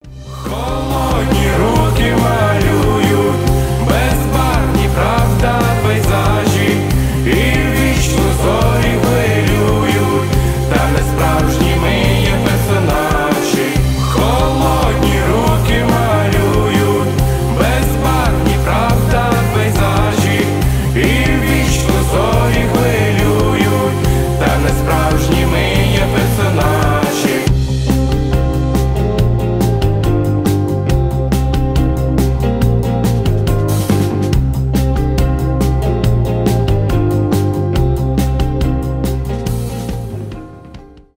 рок , пост-панк